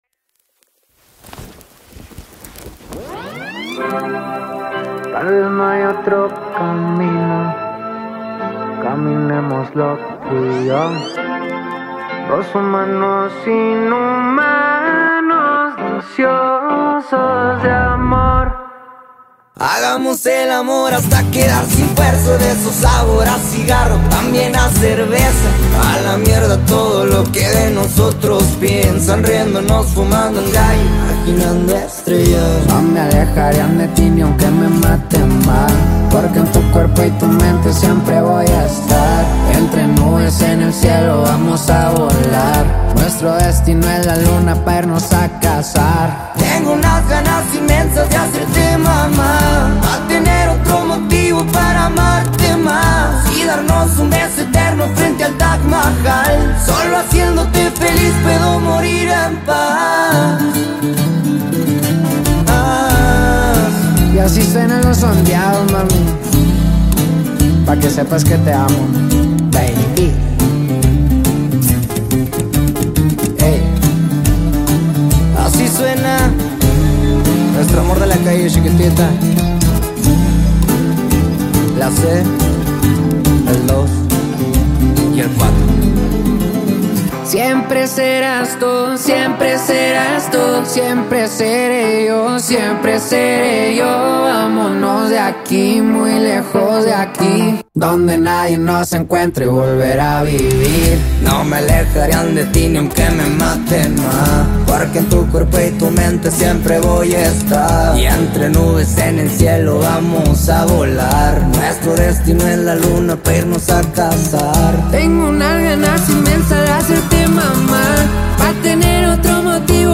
combining strong vocals